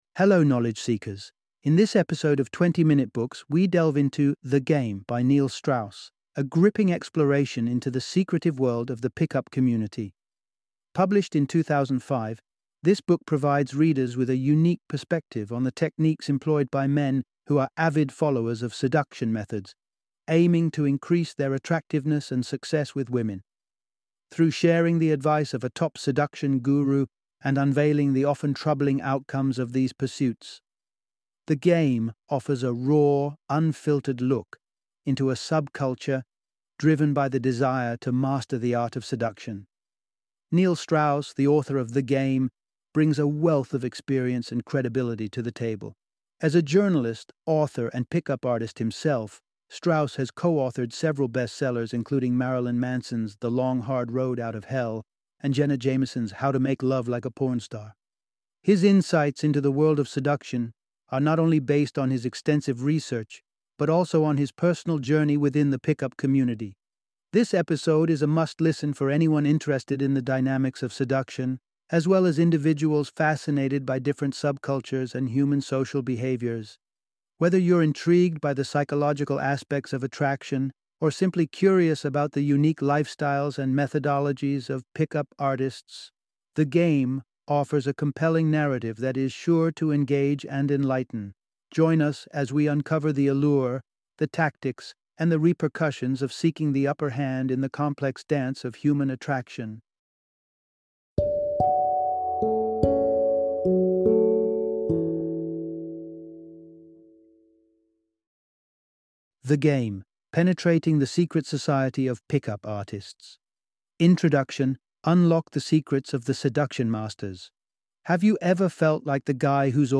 The Game - Audiobook Summary